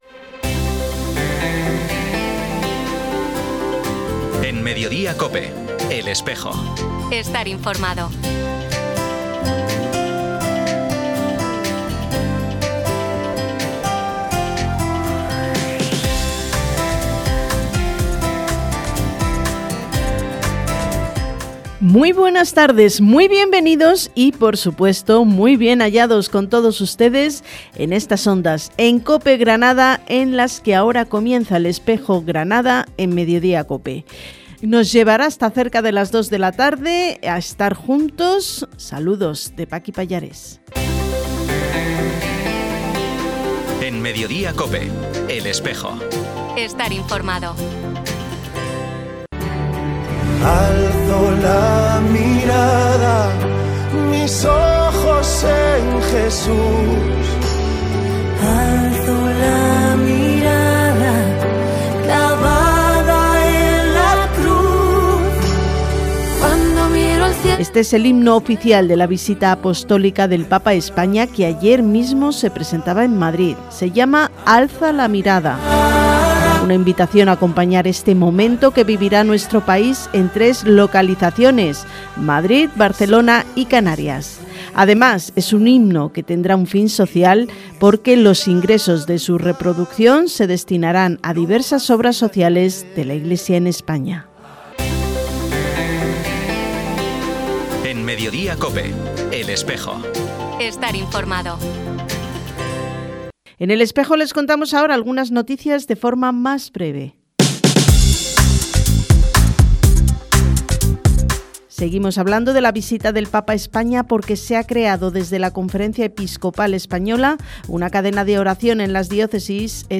Programa emitido en COPE Granada el 17 de abril de 2026.